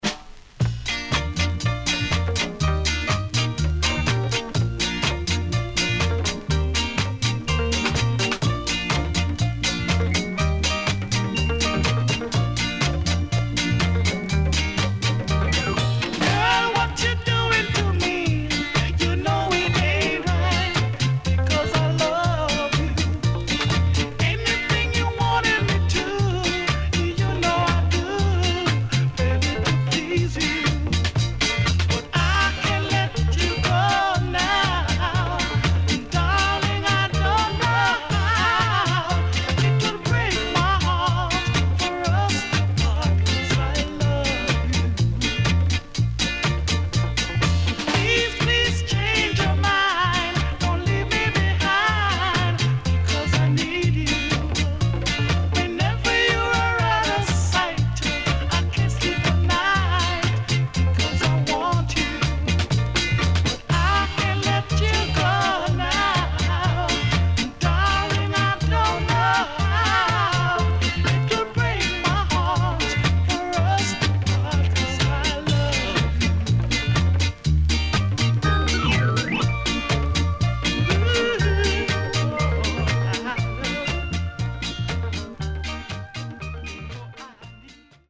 Good Early Reggae Compilation Album
SIDE A:全体的にプレス起因のノイズ入ります。